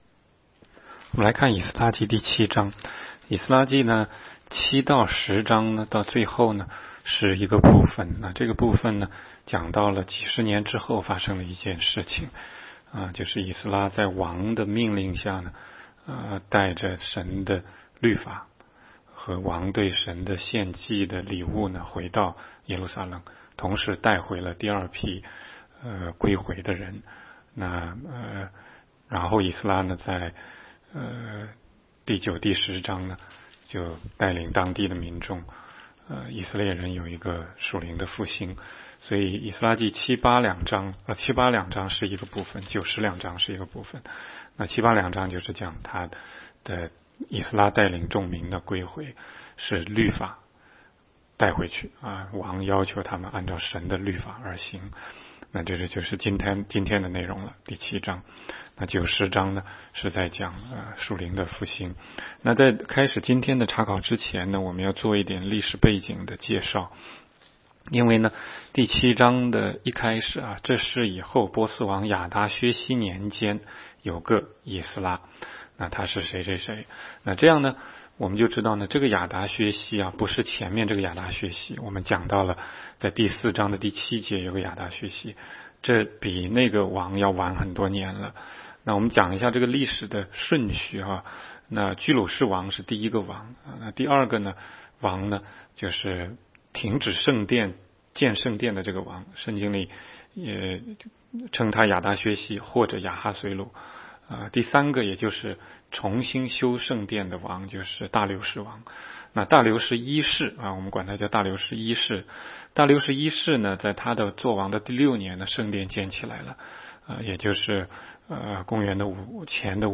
16街讲道录音 - 每日读经-《以斯拉记》7章